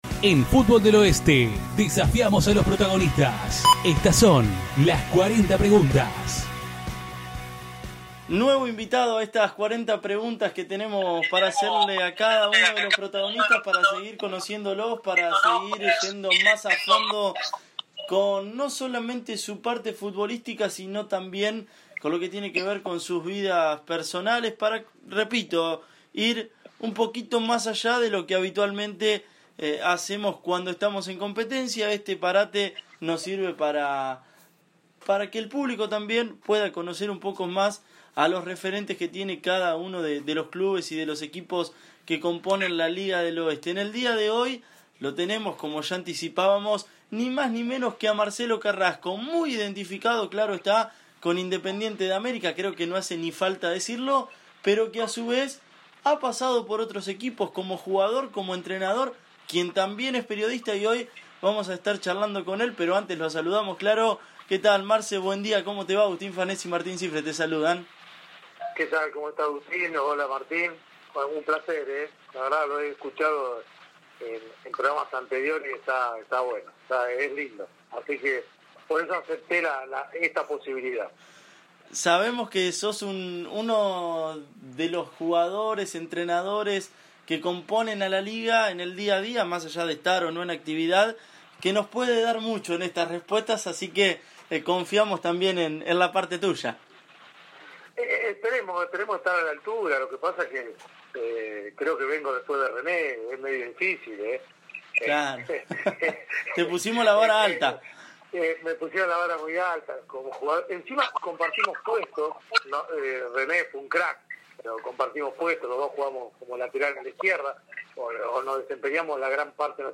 Todo eso y mucho más en esta charla.